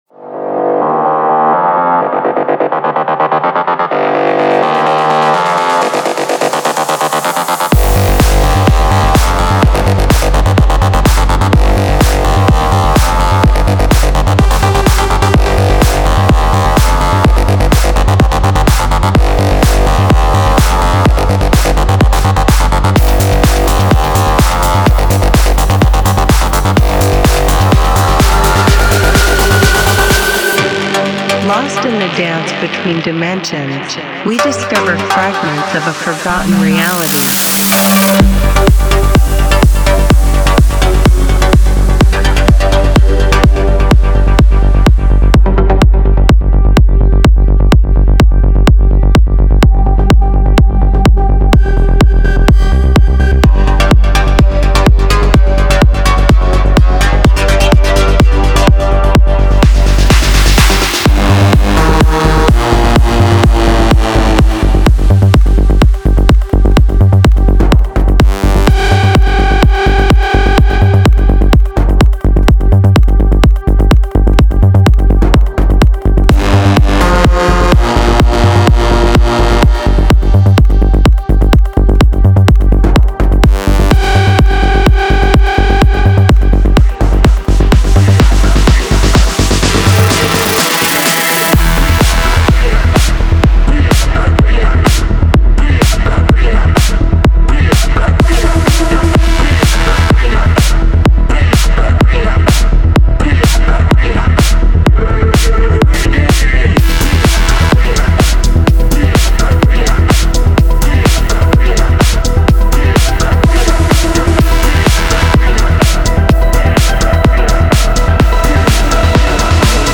Genre:Melodic Techno
内部には、豊潤なシンセループ、重厚なベースライン、緻密なドラムパターン、そして幻想的なアトモスフィアが詰まっている。
デモサウンドはコチラ↓
30 Full Drum Loops 126 Bpm